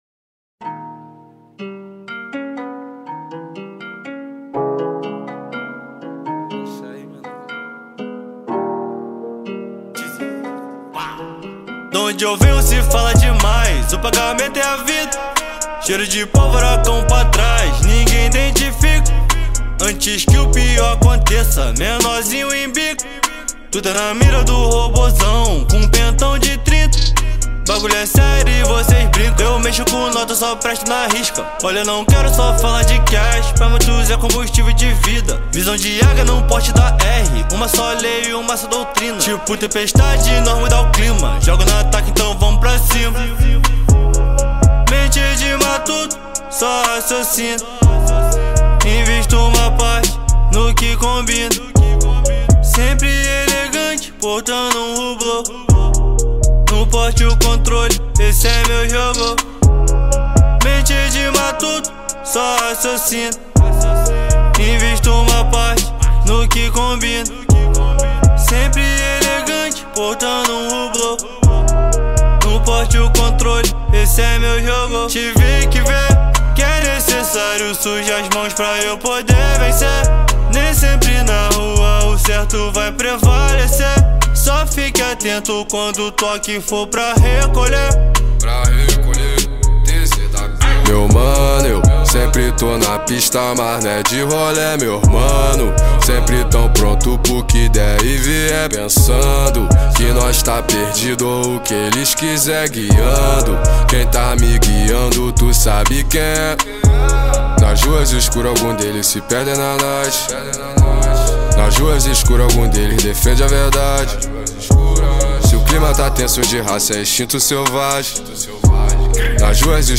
2024-07-23 14:40:18 Gênero: Funk Views